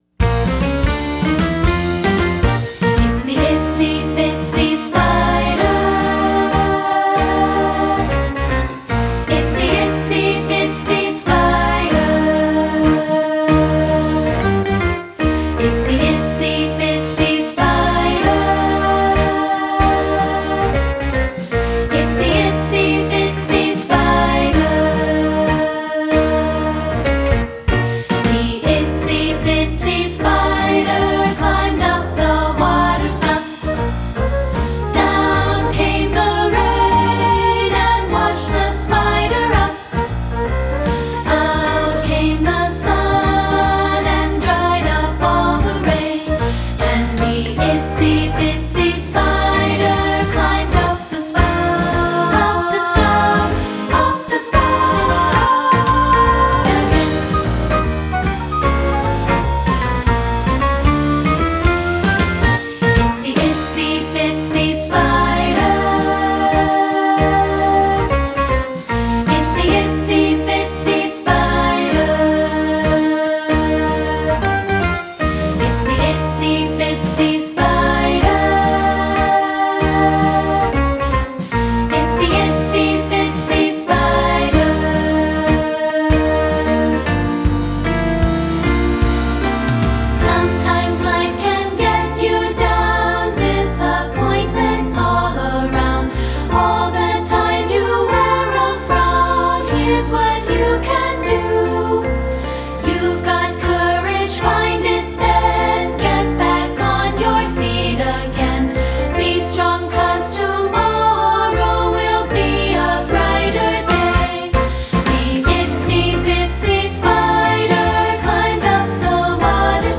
Instrumentation: choir (2-Part)
secular choral